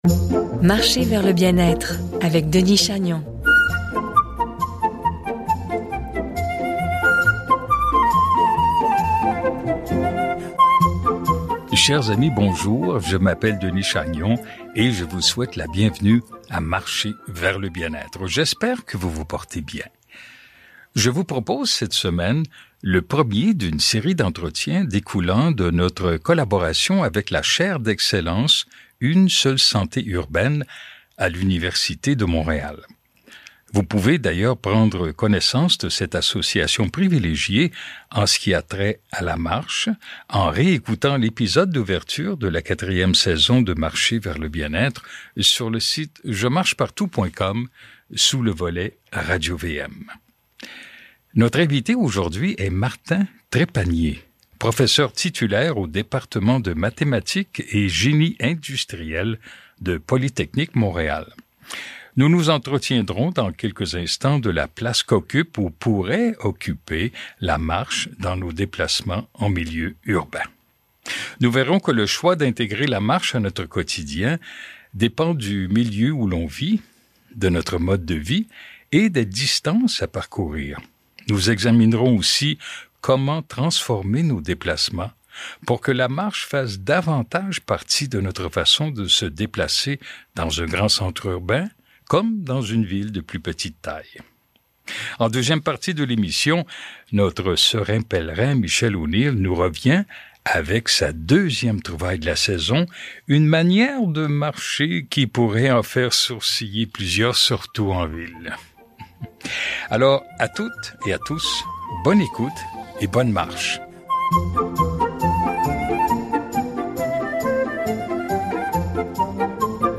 1 octobre 2024 — Je vous propose cette semaine le premier d’une série d’entretiens découlant de notre collaboration avec la Chaire d’excellence Une seule santé urbaine à l’université de Montréal.